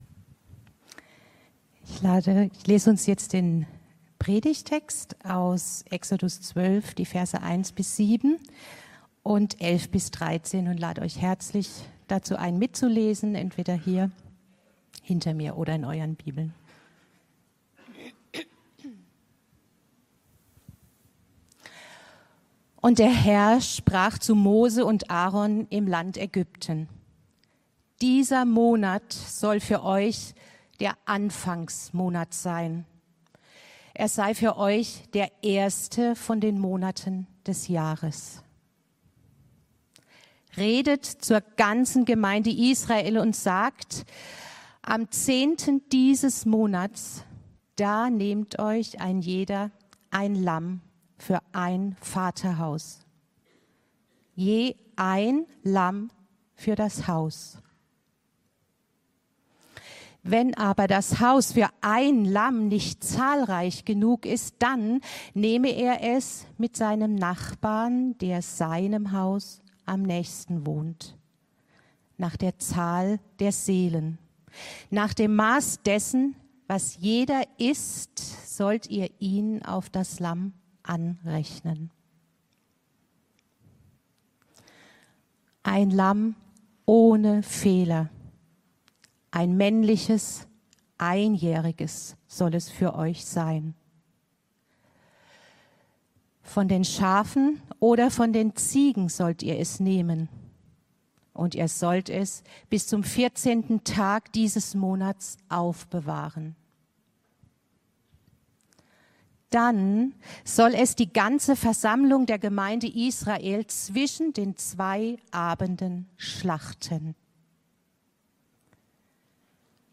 Predigten – Er-lebt.